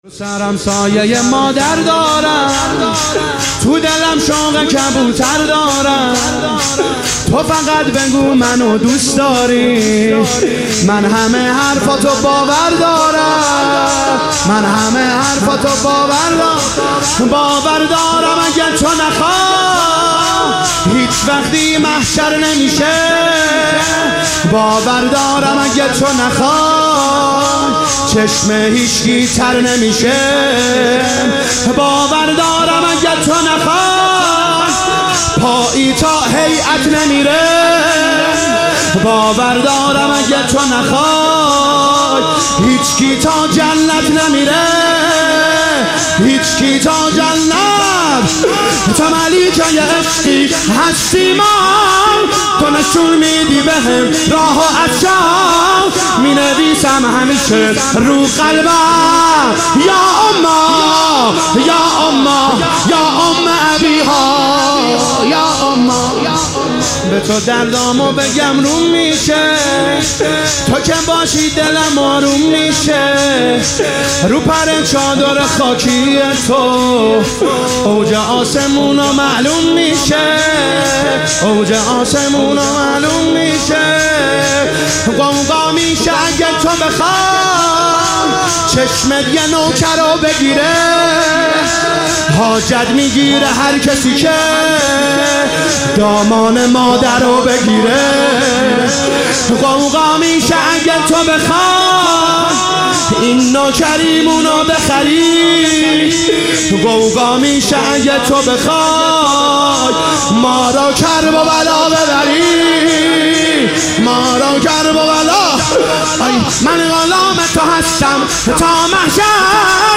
ایام فاطمیه 1441 | هیئت رزمندگان اسلام قم